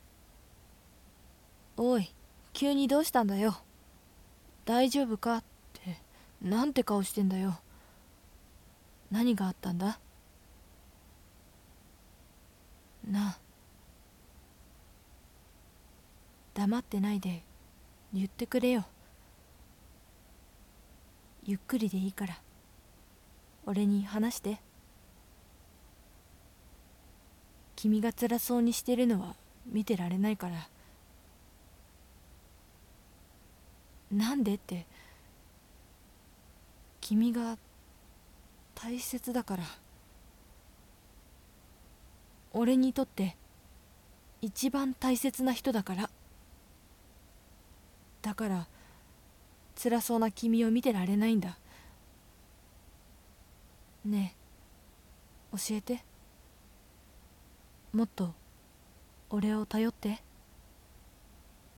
シチュエーションボイス